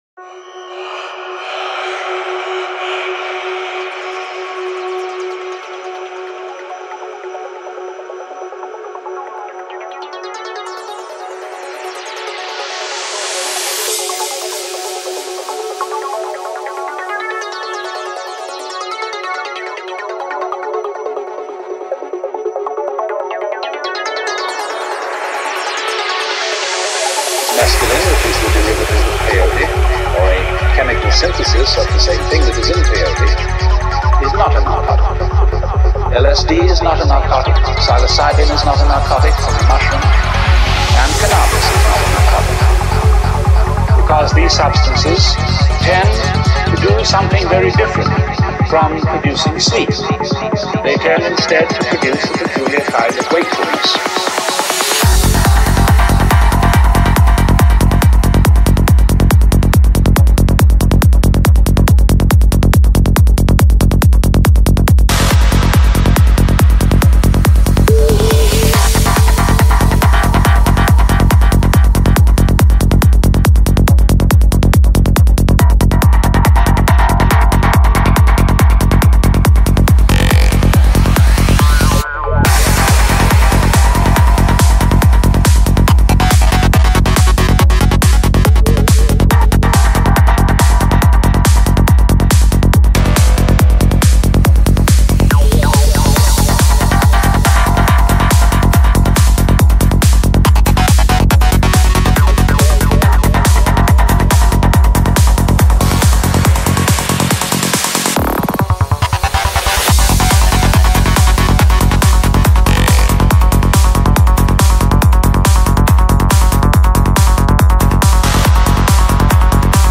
Жанр: Psychedelic
Psy-Trance Скачать 8.39 Мб 0 0 0